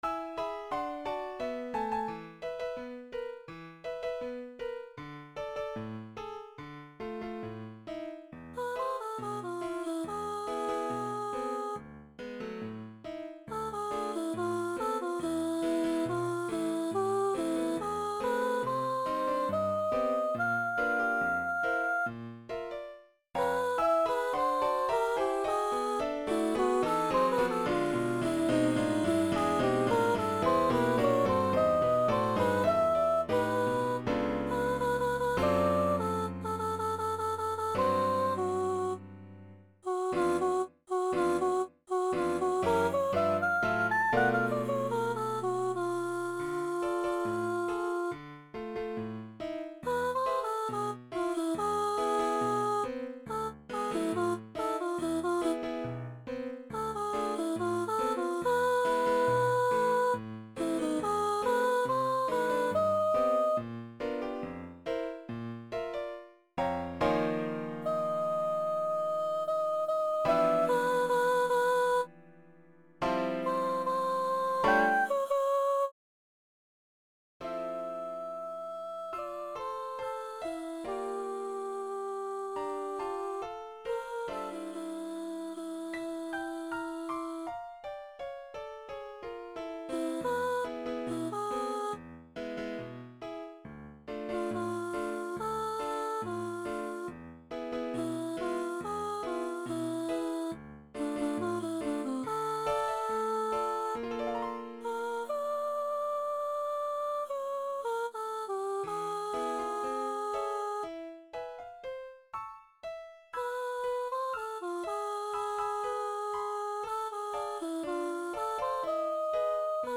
High voice / piano